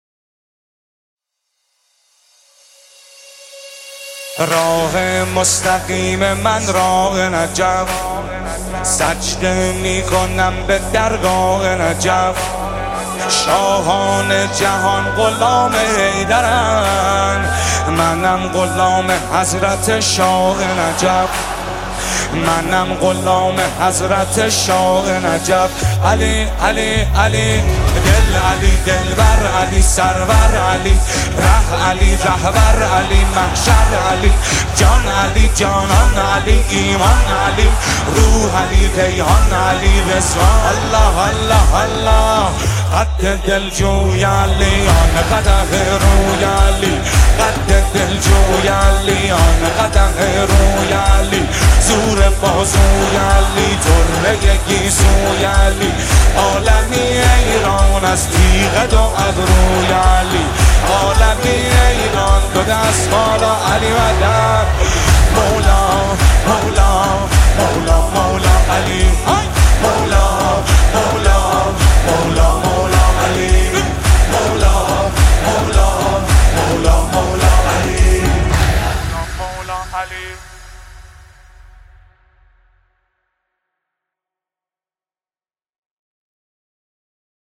مداحی غم انگیز
مداح نوحه